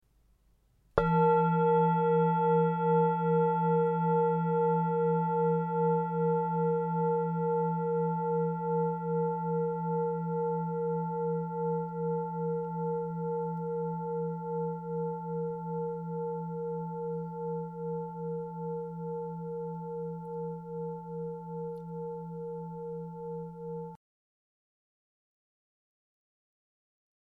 Tibetische  Klangschale HERZSCHALE 1234g KM67B
Gewicht: 1234 g
Durchmesser: 21,3 cm
Grundton: 166,93 Hz
1. Oberton: 462,98 Hz